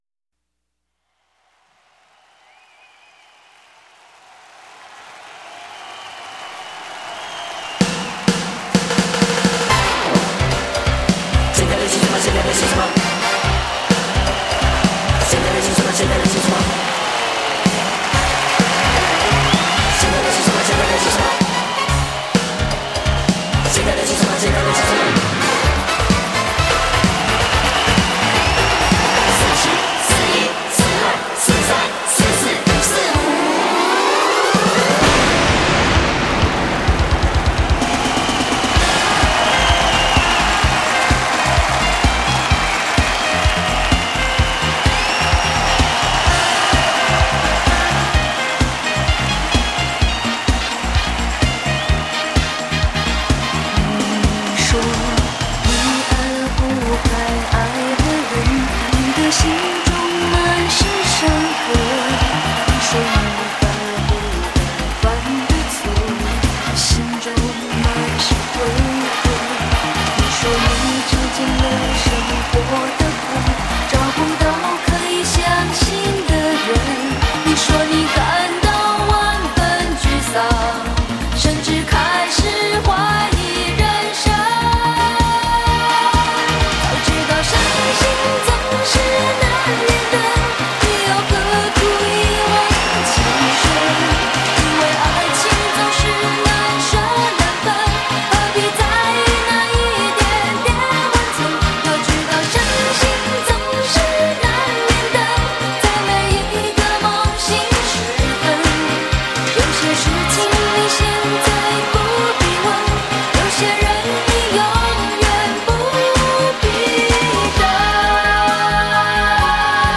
港台流行金曲大联唱
45就是 采45转快转的方式演唱串联当红歌曲的组曲 记录着70.80年代台湾流行乐史